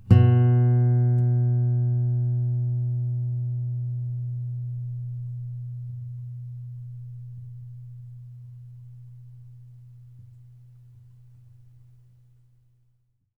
bass-06.wav